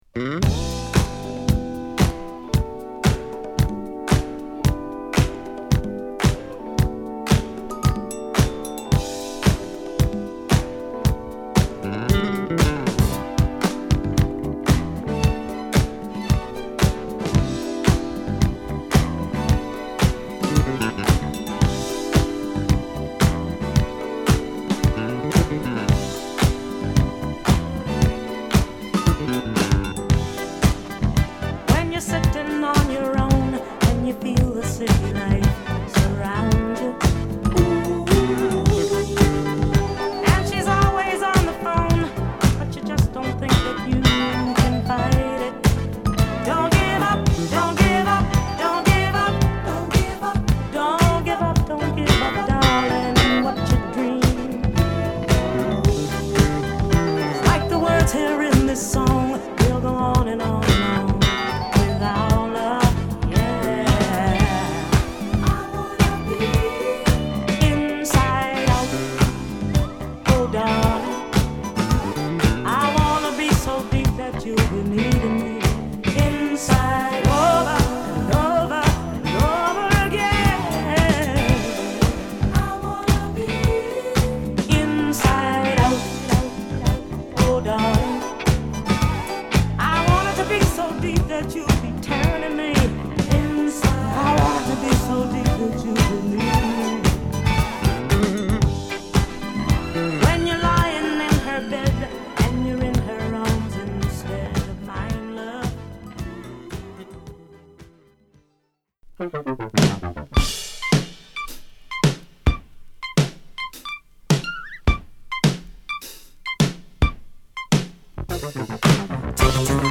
ミッドテンポのディスコブギーチューンを披露！